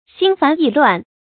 注音：ㄒㄧㄣ ㄈㄢˊ ㄧˋ ㄌㄨㄢˋ
讀音讀法：